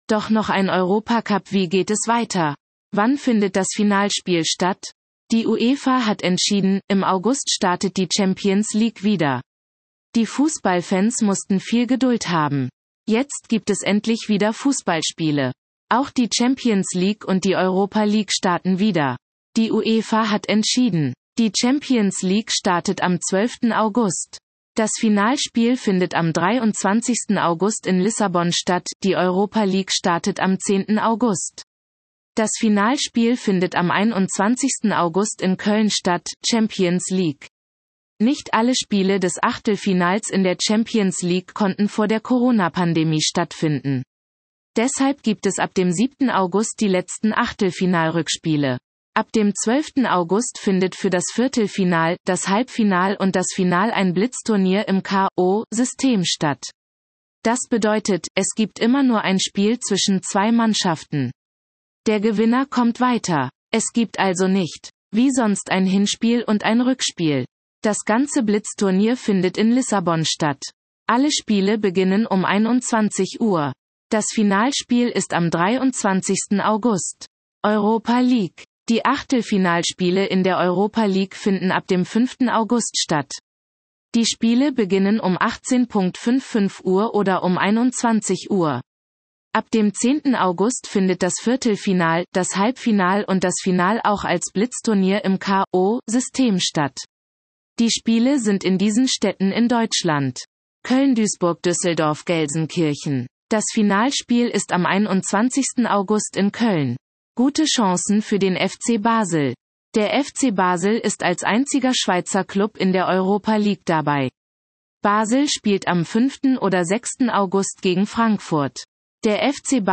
Vorlesen